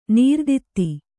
♪ nīrditti